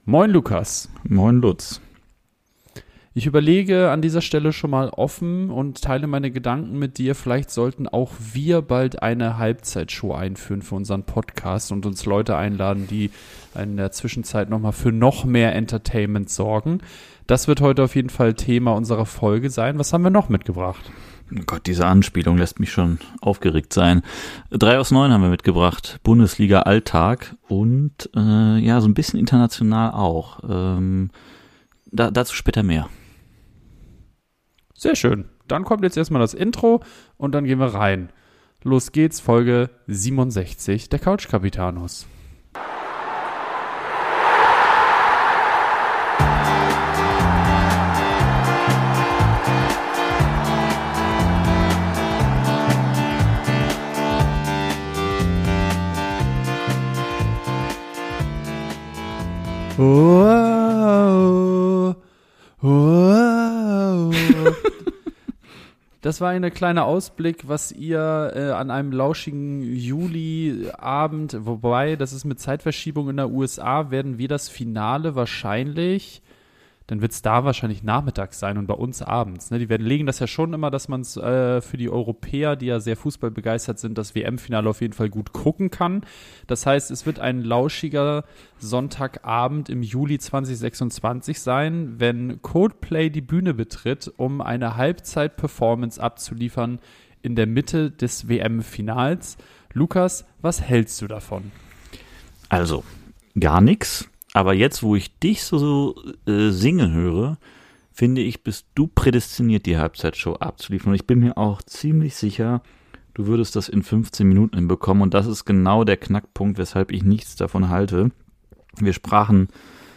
Ab Minute 34 könnt ihr die Coldplay Halbzeit Show in unserer Folge 67. hören.